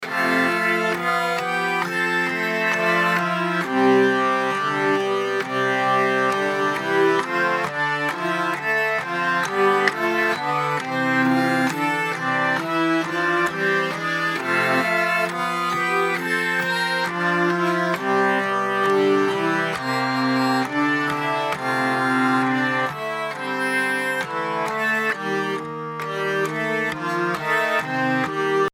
This is a relaxing, simple and short melody. I had a nostalgic feeling from this, so I put some production to enhance that effect.
I've also made short versions of the loop with each instrument used.
Forgotten Lullaby Hurdy Gurdy Loop.mp3